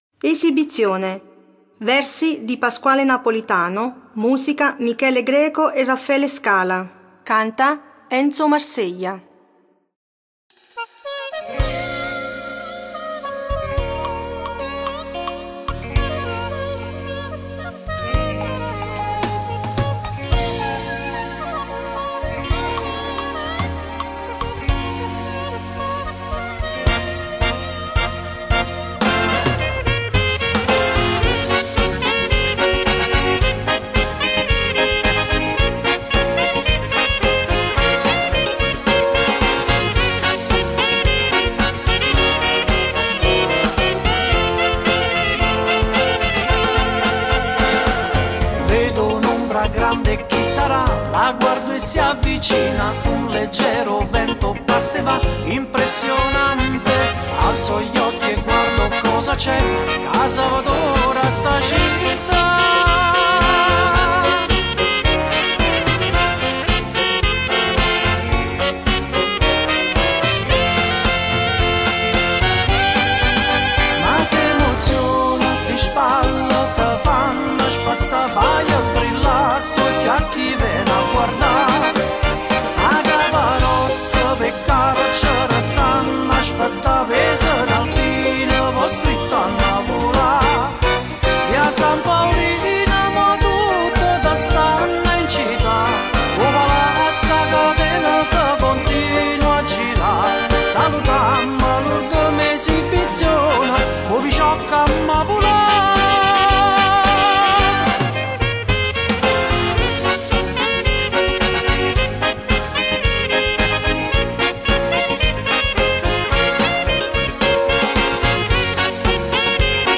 Esibizione